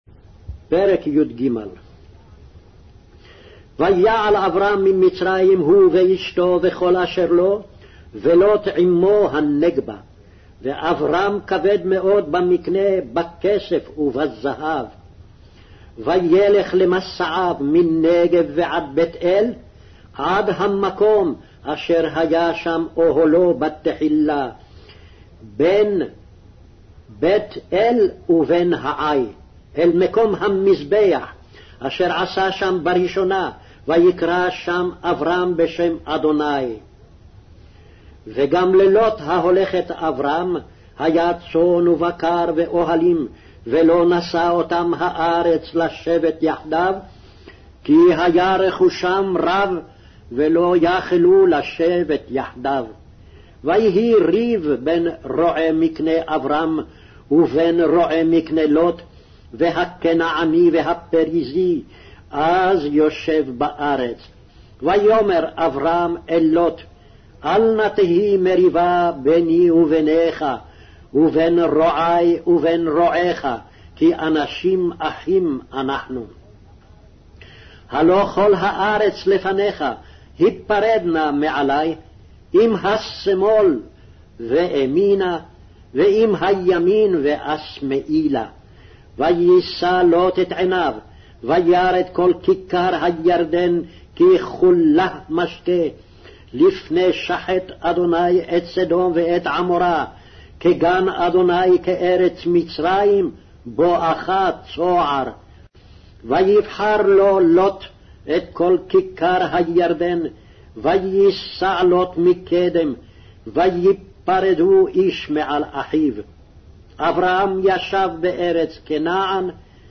Hebrew Audio Bible - Genesis 5 in Kjv bible version